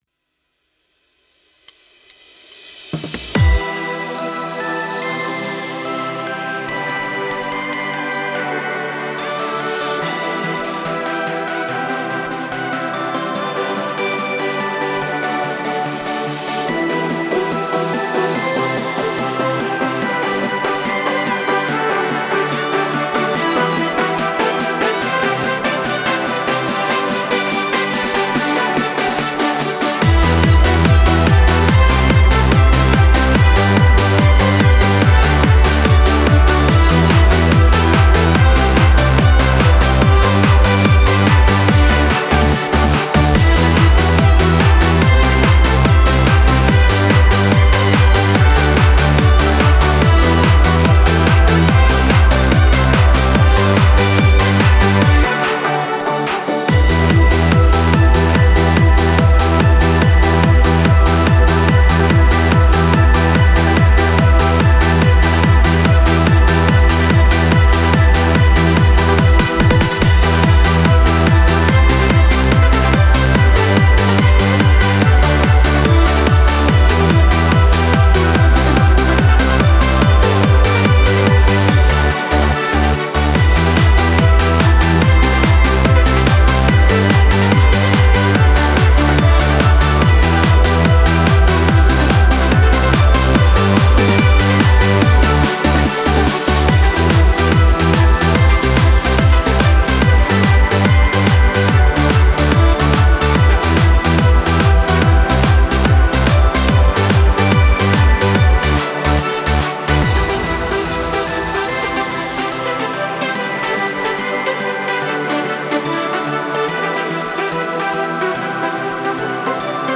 a Hands Up song